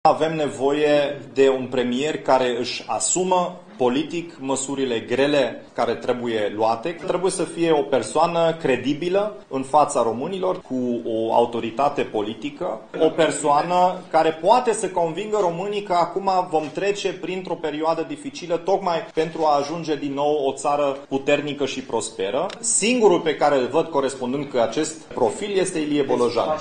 Dominic Fritz, ales ieri în funcţia de preşedinte al formaţiunii, a explicat că Ilie Bolojan ar fi singurul care corespunde cerinţelor postului: